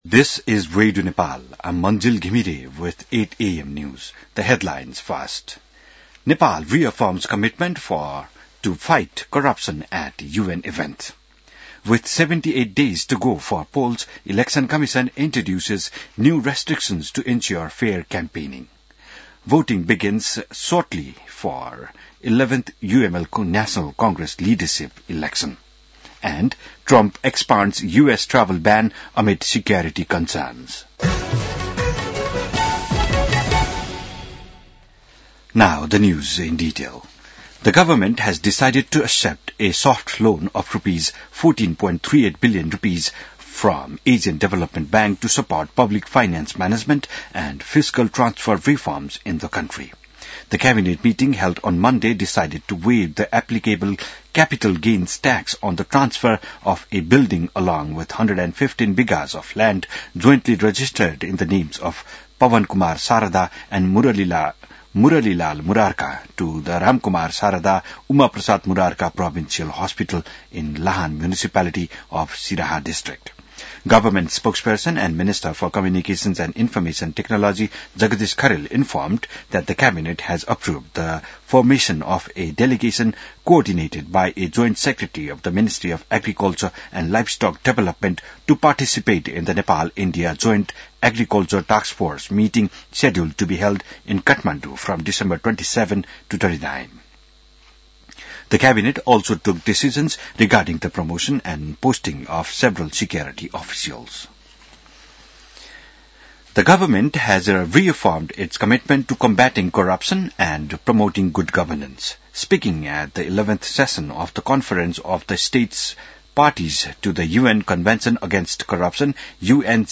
बिहान ८ बजेको अङ्ग्रेजी समाचार : २ पुष , २०८२